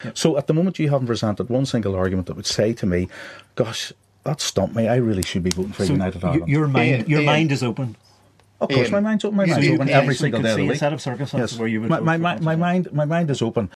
Speaking on the podcast